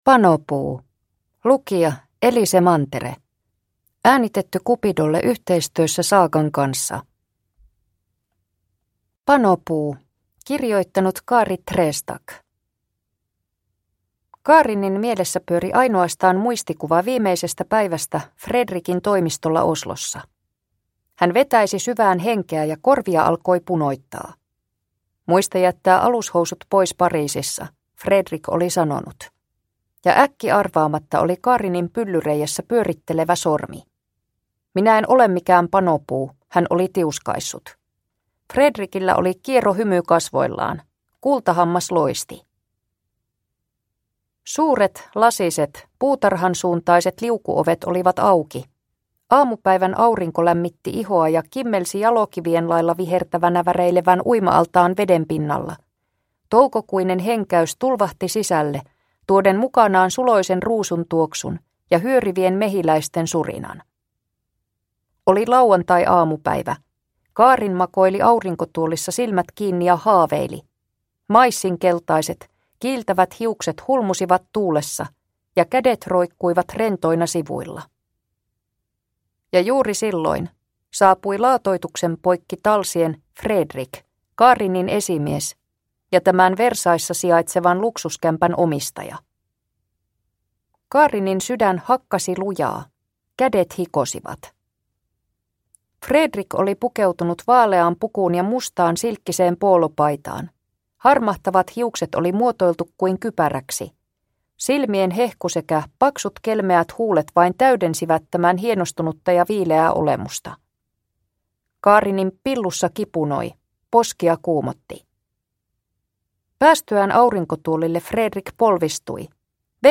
Panopuu (ljudbok) av Cupido